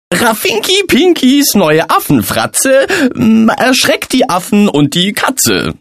The characters' voices are well chosen and mostly pleasant.